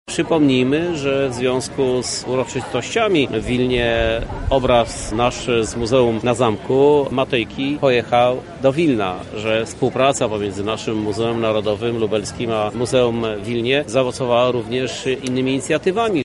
Głos w tym temacie zabrał też Krzysztof Żuk, prezydent Lublina: